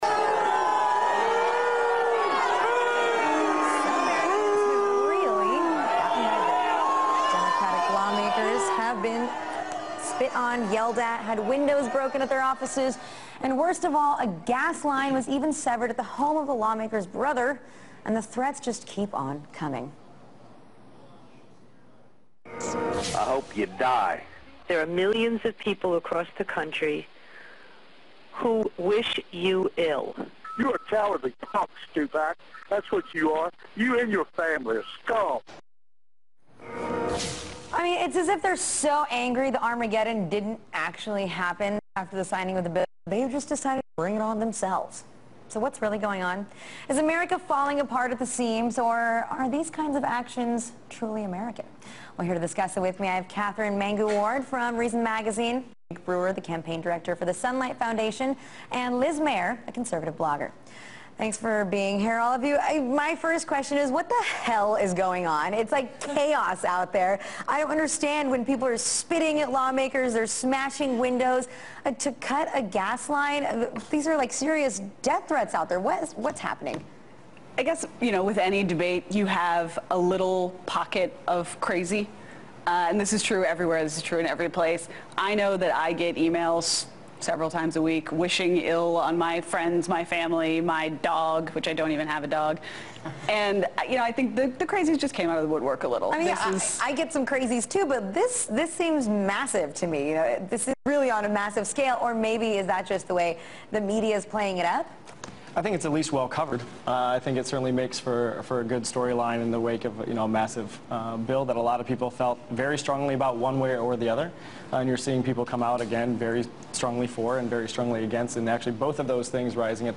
joined a panel to discuss the backlash against the passage of the health care bill on Russia Today's "The Alyona Show."